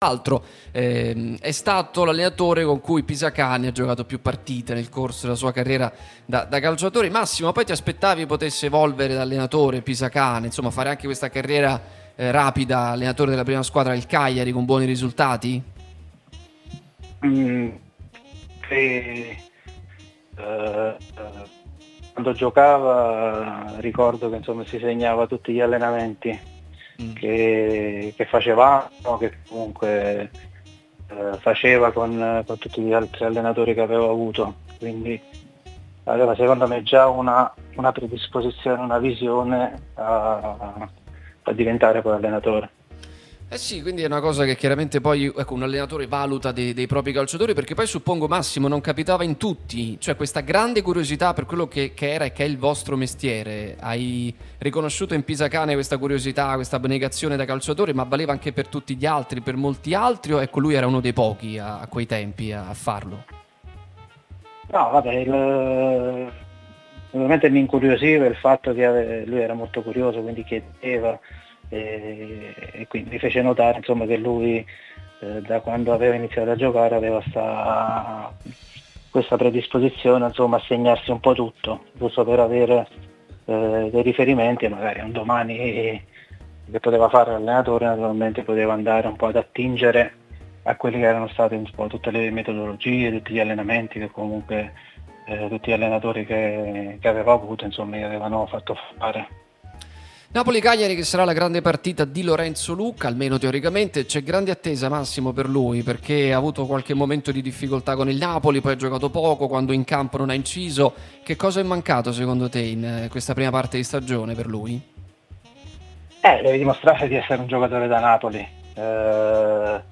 L'allenatore Massimo Rastelli è intervenuto nel corso di Pausa-caffè sulla nostra Radio Tutto Napoli, prima radio tematica sul Napoli, in onda tutto il giorno, che puoi ascoltare/vedere qui sul sito o sulle app (qui per Iphone/Ipad o qui per Android) per commentare l'attesa per la vigilia della sfida di oggi tra Napoli e Cagliari: